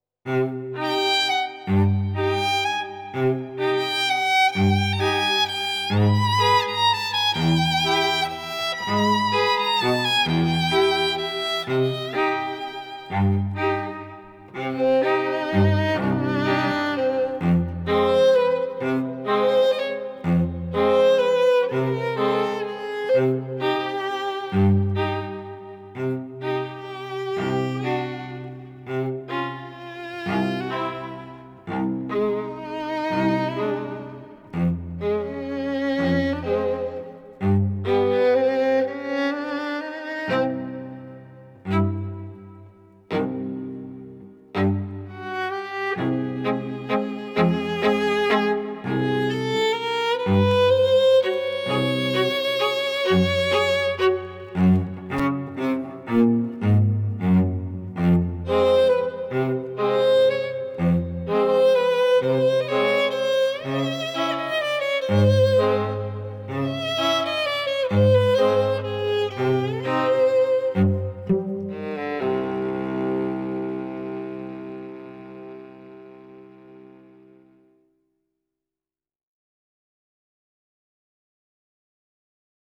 ژانر: بی کلام